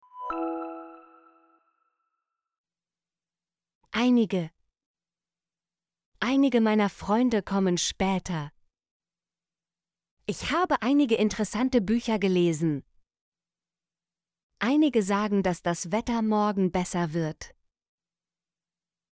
einige - Example Sentences & Pronunciation, German Frequency List